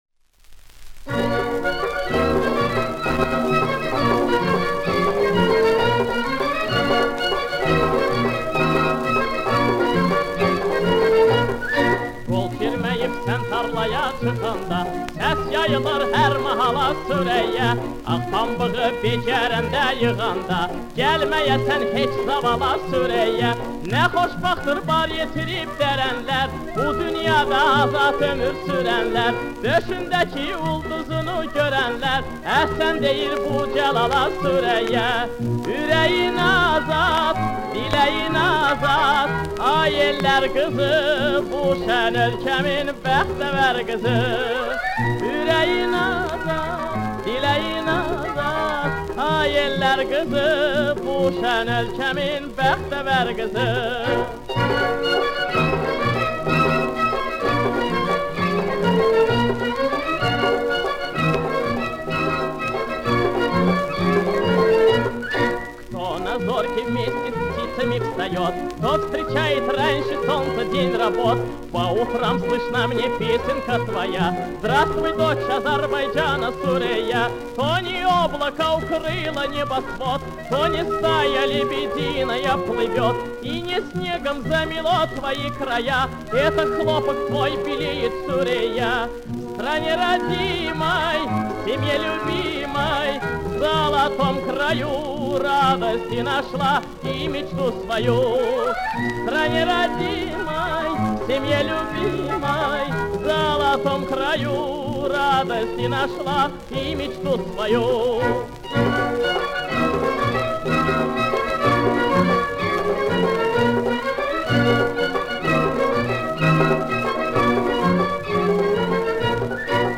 Исполняется на двух языках.
Как всегда - праздник мелодии.